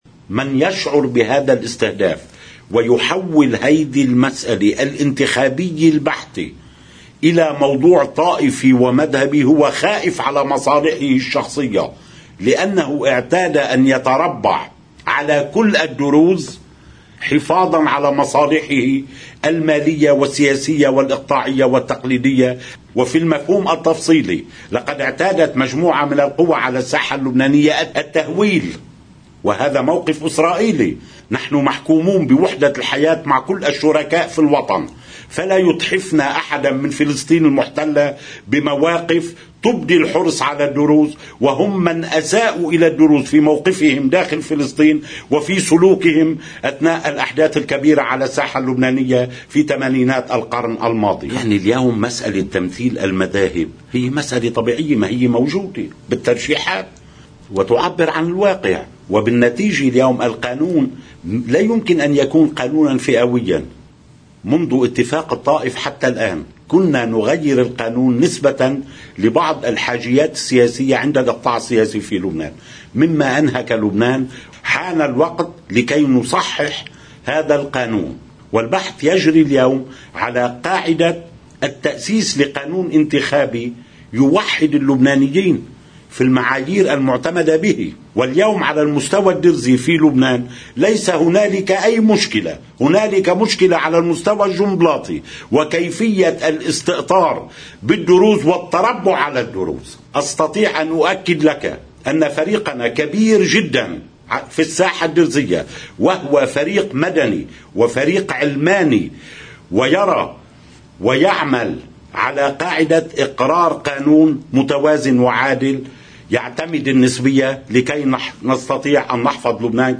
مقتطف من حديث النائب فادي الأعور لقناة الـ”OTV”: